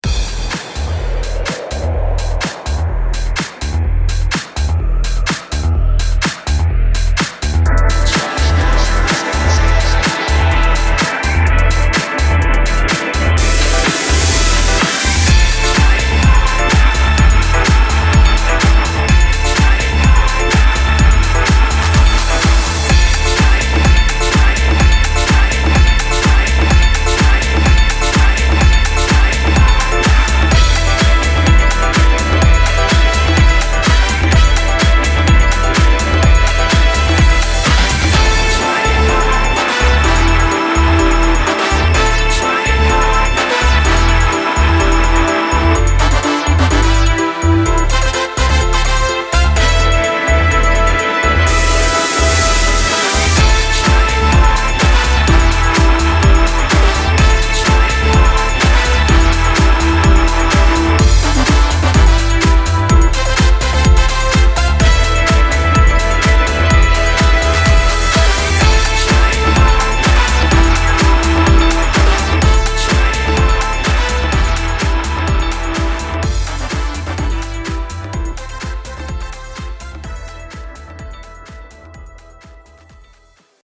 Vocals
Keyboards
Drums